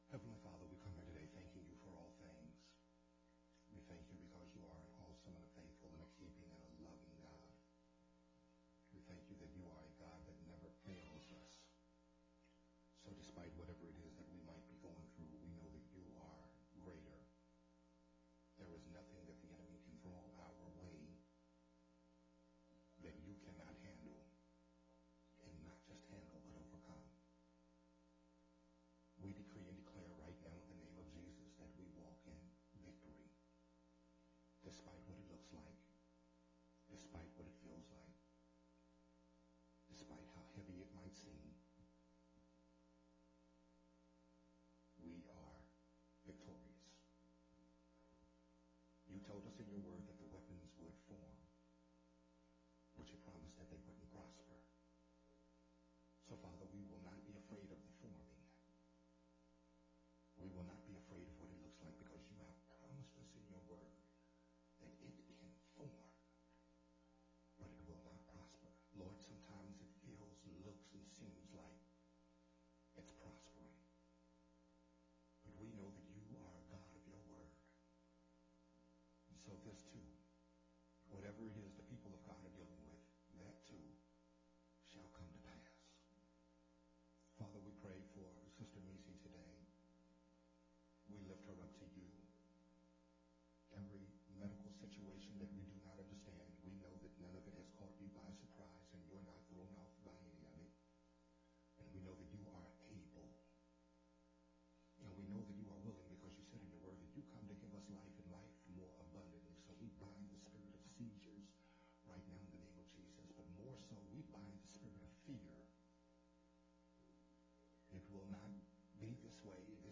Growth Temple Ministries Audio